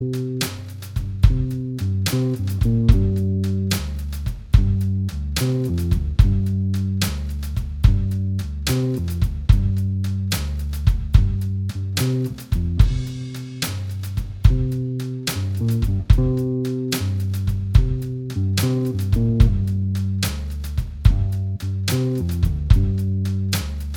Minus Guitars Rock 3:16 Buy £1.50